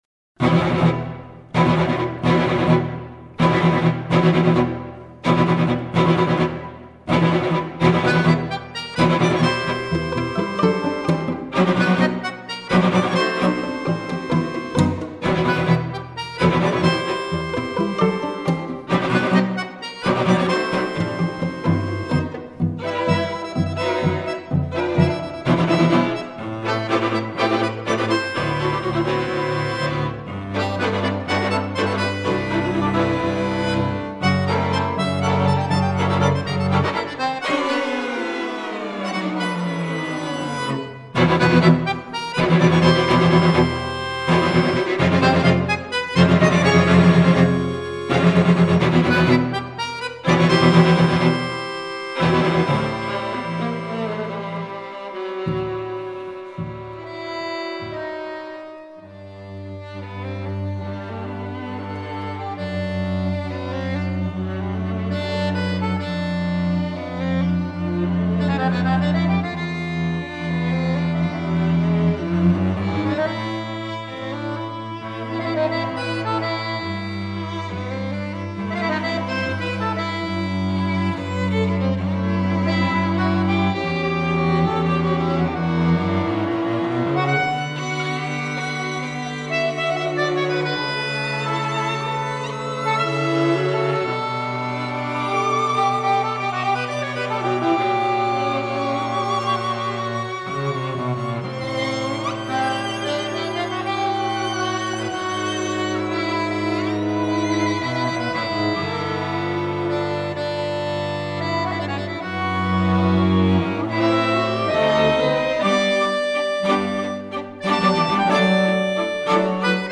per fisarmonica e quartetto d’archi